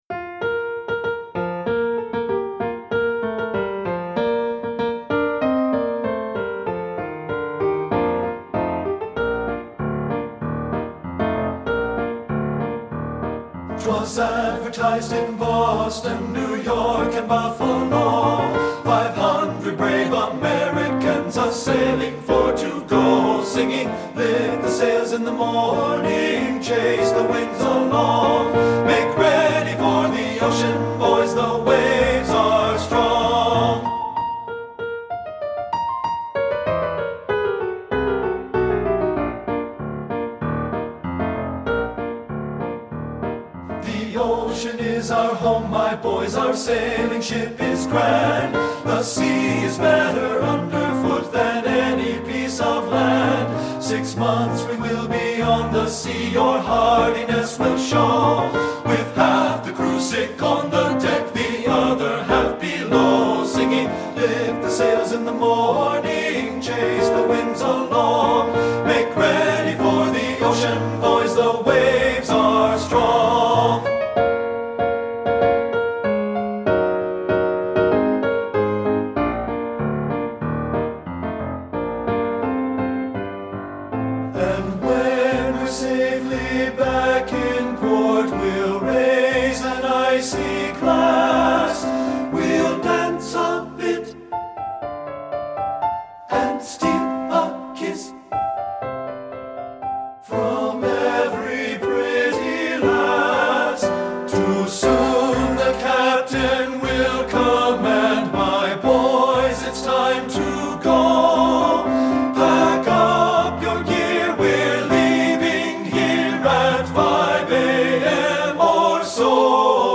Composer: Sea Chantey
Voicing: TB and Piano